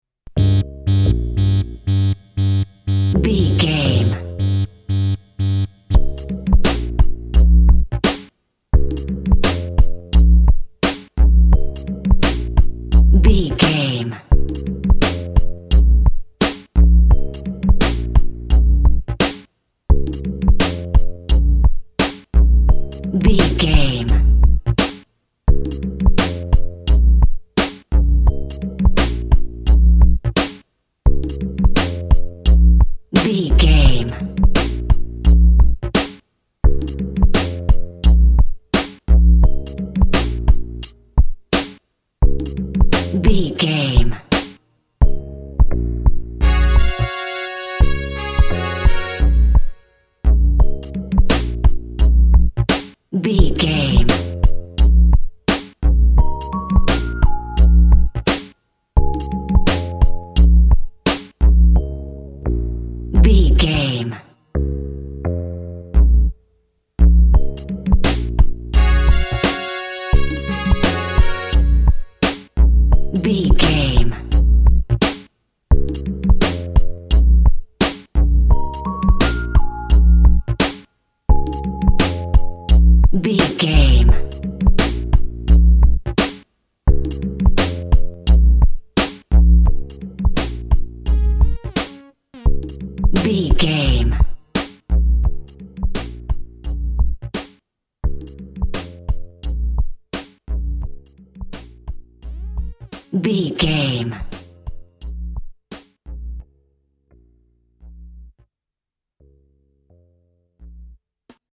Hip Hop Waking Up.
Aeolian/Minor
B♭
synth lead
synth bass
hip hop synths